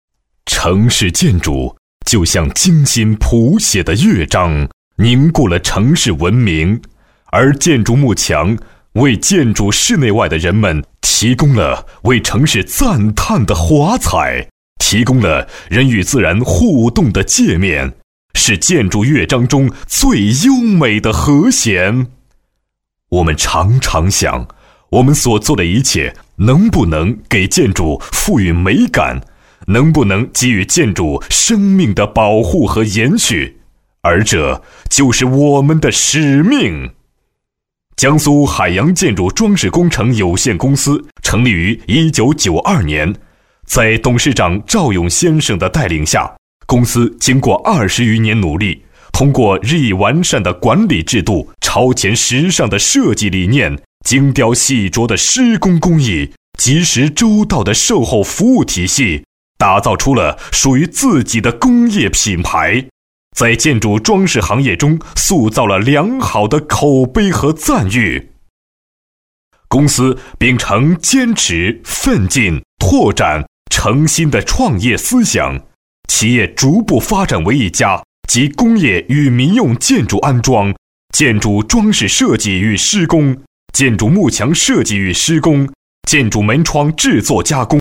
特 点：大气浑厚 稳重磁性